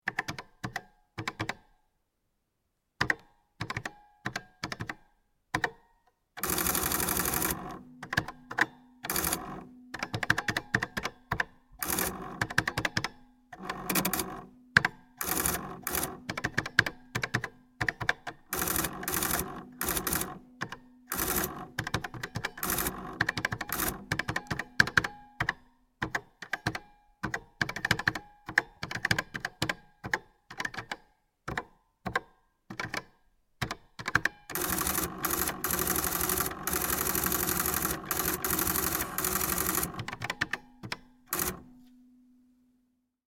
Sanyo Serd Modell 71 electronic calculating machine
This is part of the Obsolete Sounds project , the world’s biggest collection of disappearing sounds and sounds that have become extinct – remixed and reimagined to create a brand new form of listening.